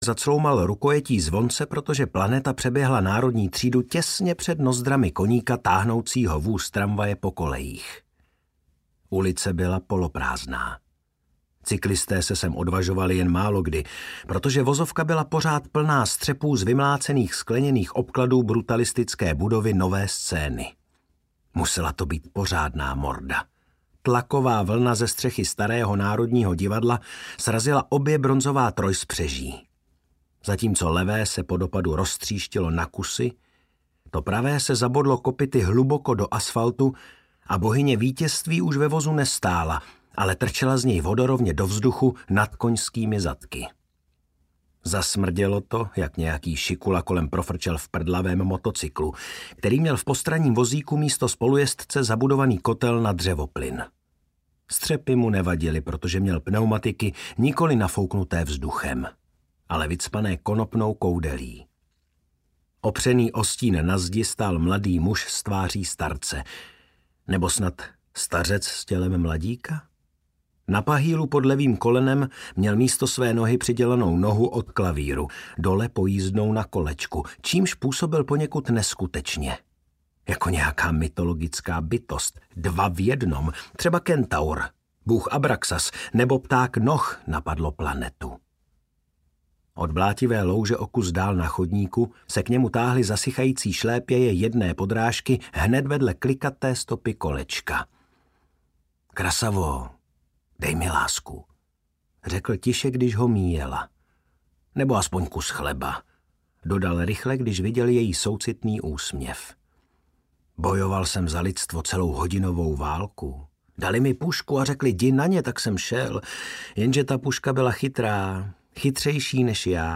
Zasvěcení temnotou audiokniha
Ukázka z knihy
• InterpretMartin Písařík